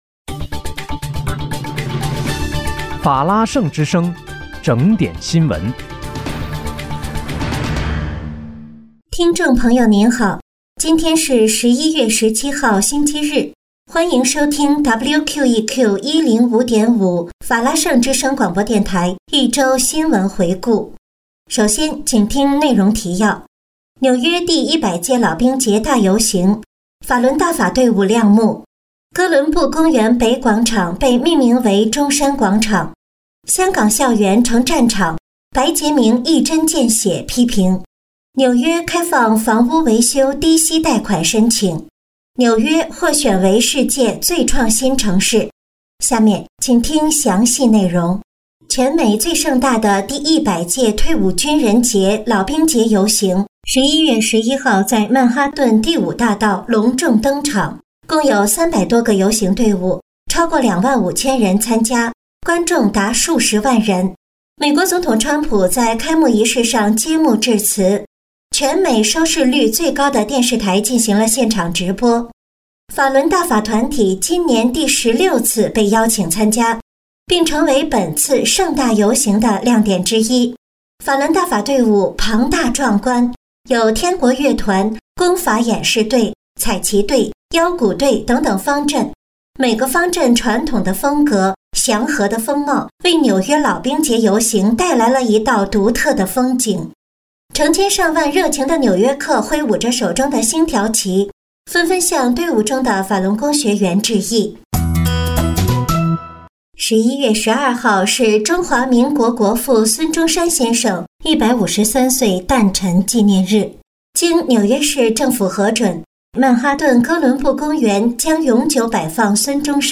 11月17号（星期日）一周新闻回顾